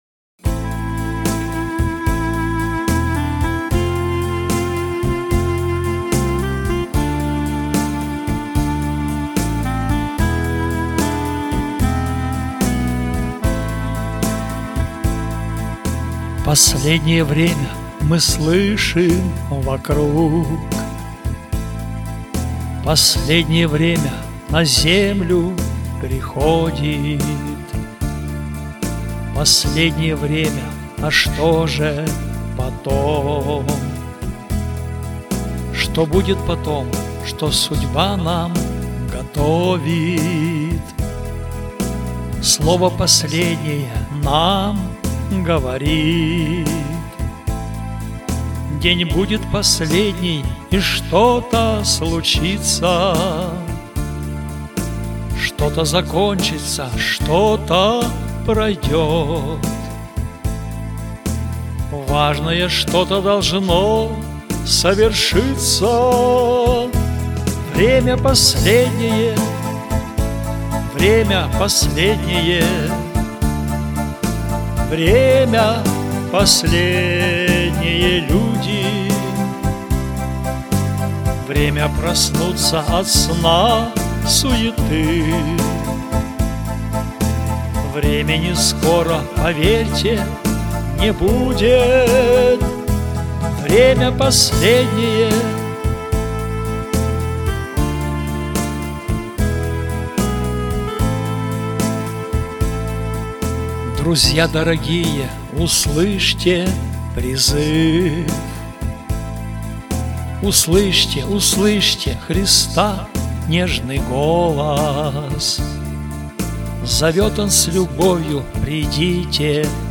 Теги: Христианские песни